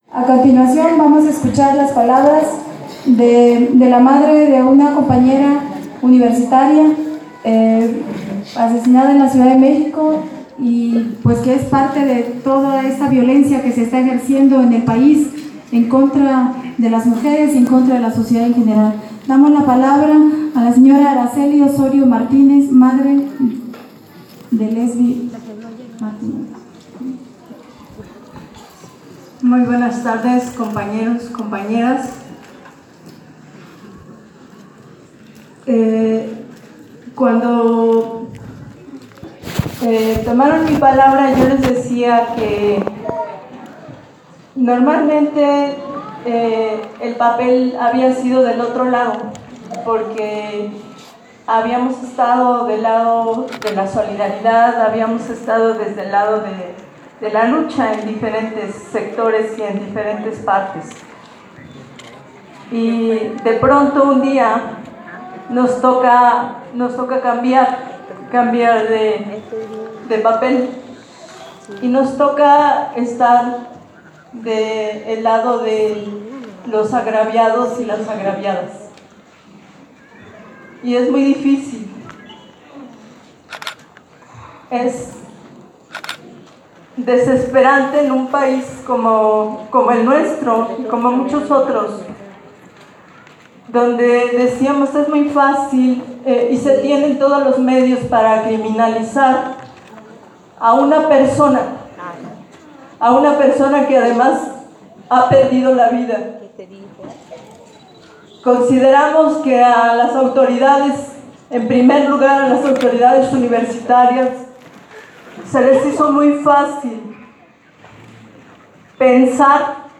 Aquí les compartimos los audios de la sesión pública de la Asamblea Constitutiva del Consejo Indígena de Gobierno para México, realizada el 28 de mayo de 2017 en el CIDECI-Unitierra en San Cristóbal de las Casas, Chiapas: